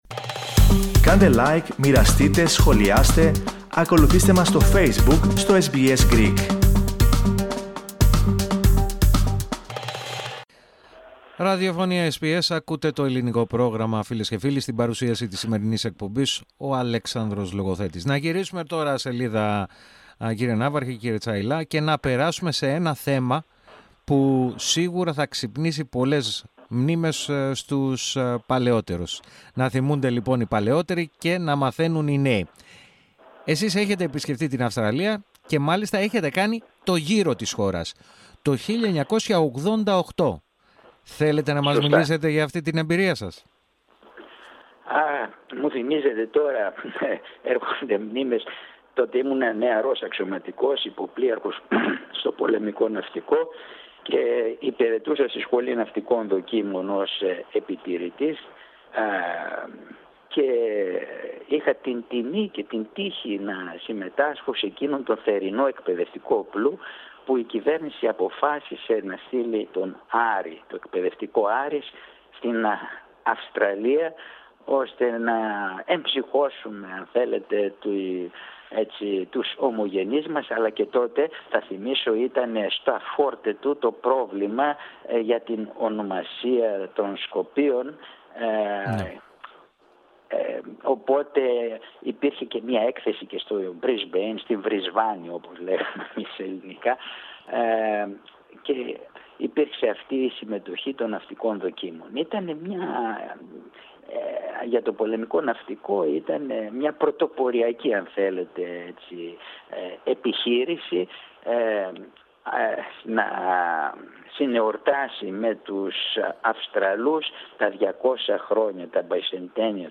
μοιράστηκε με το Ελληνικό Πρόγραμμα της ραδιοφωνίας SBS
με τρεμάμενη, από τη συγκίνηση, φωνή